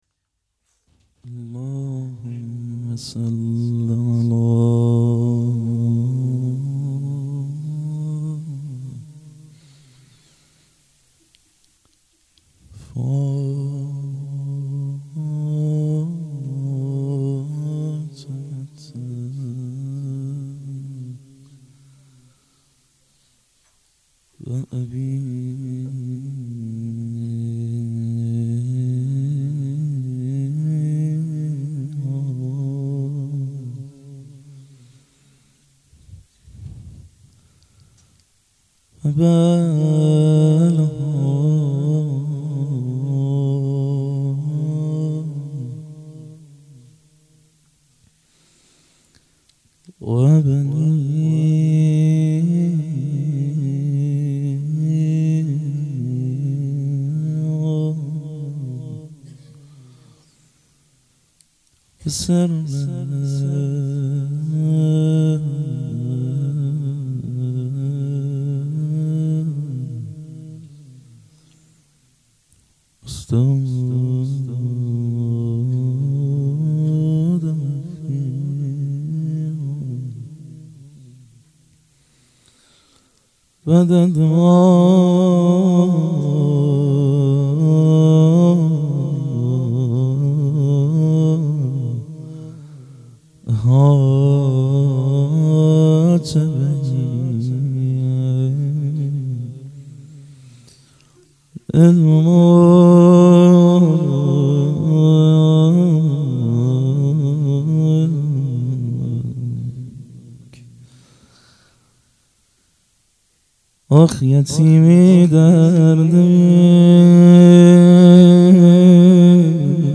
اقامه عزای شهادت حضرت زهرا سلام الله علیها _ دهه دوم فاطمیه _ شب اول